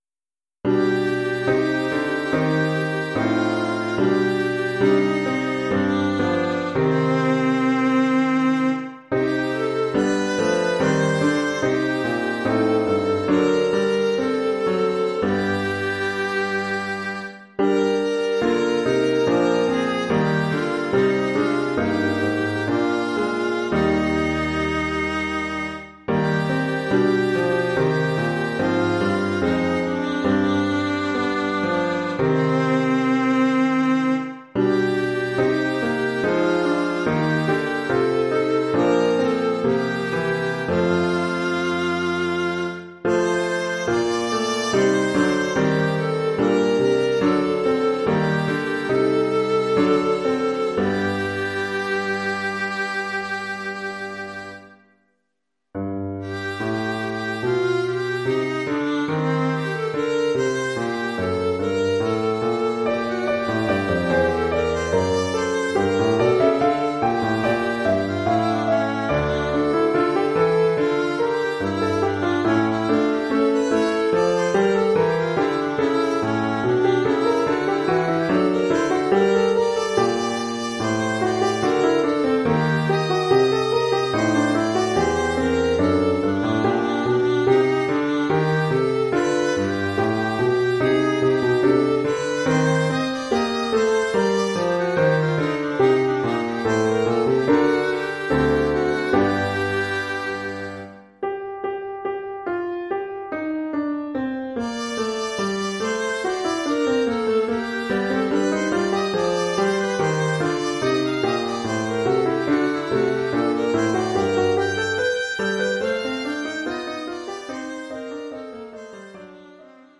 Oeuvre pour alto et piano..